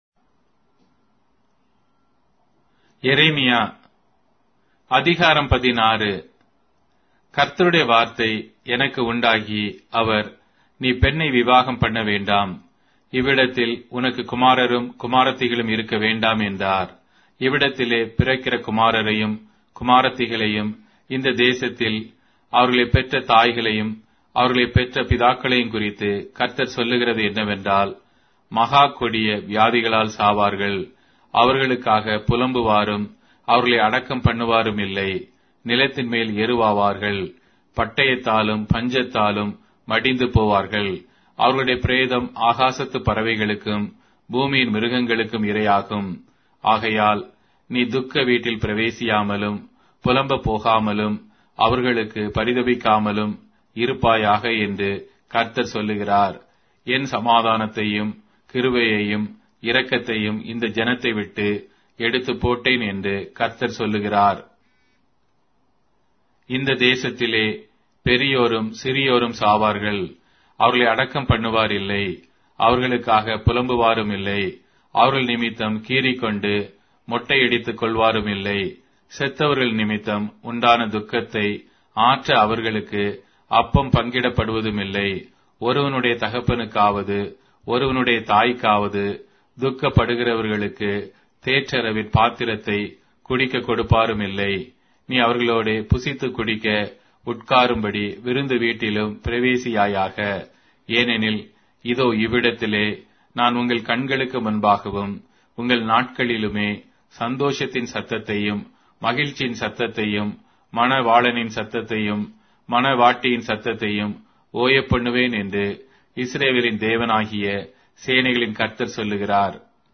Tamil Audio Bible - Jeremiah 47 in Erven bible version